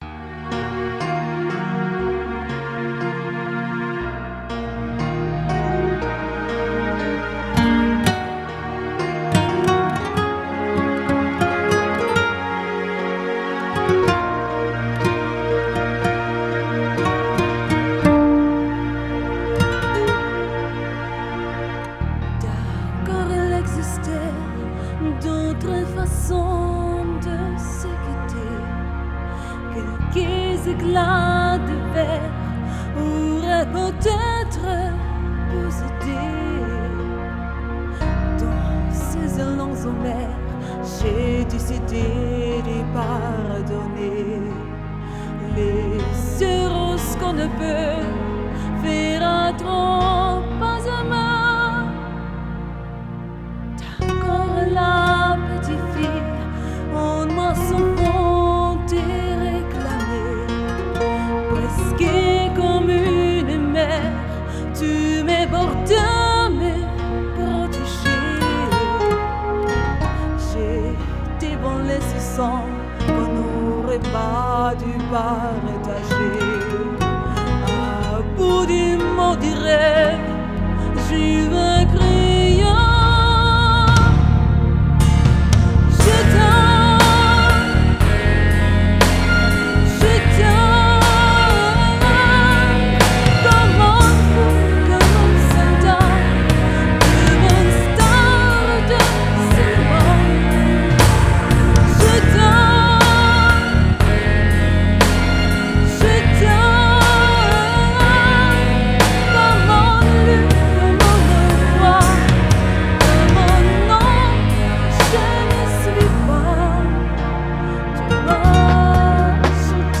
Hochzeitssängerin
Berührender Live-Gesang